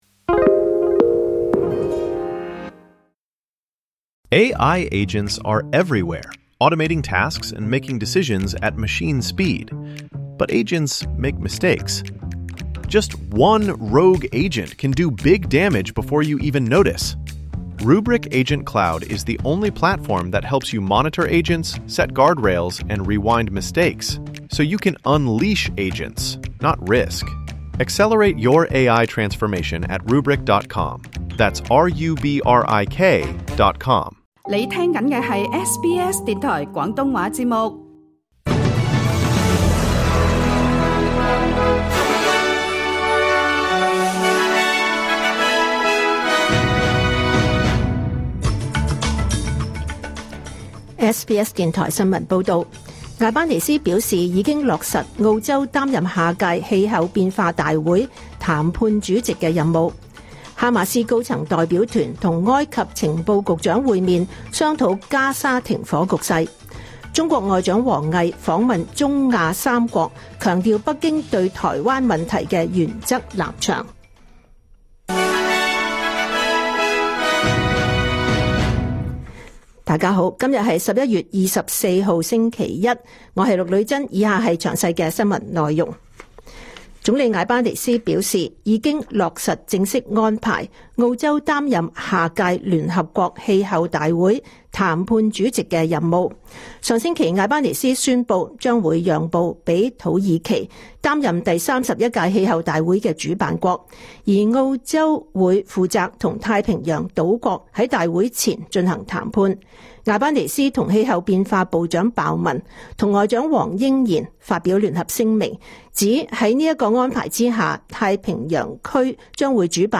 2025 年 11 月 24 日 SBS 廣東話節目詳盡早晨新聞報道。